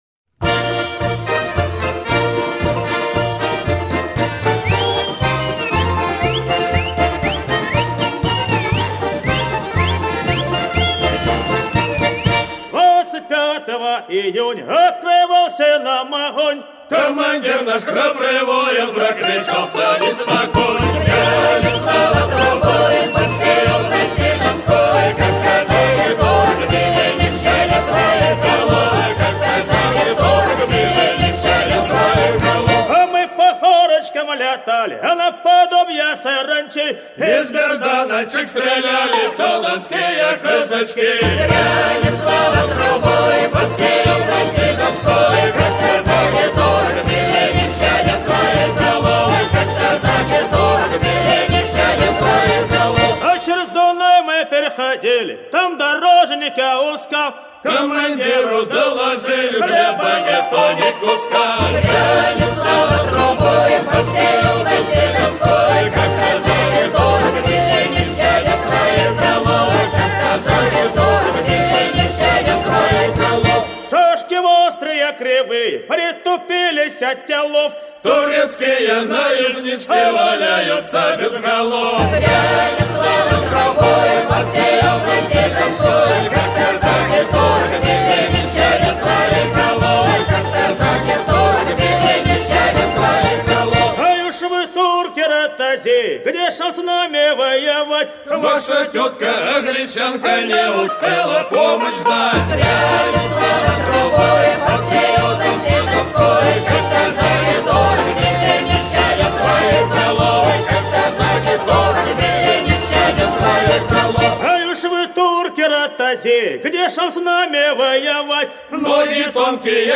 Вот более энергичная: